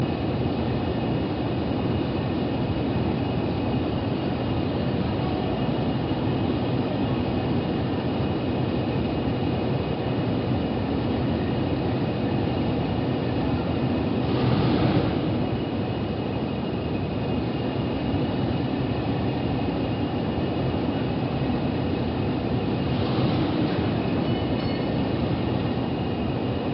At Motherwell DD on 16.2.77, 40.136 idling in No. 2 Road outside the shed
moves off to the North end of the Depot. 25.026 then moves through No. 2
South end 27.025 arrives in No. 1 Road inside the Shed to fuel, and from the
Stereo MP3 128kb file